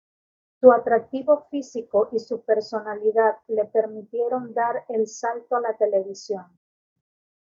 Read more Frequency B2 Hyphenated as sal‧to Pronounced as (IPA) /ˈsalto/ Etymology Borrowed from Latin saltus In summary Borrowed from Latin saltus.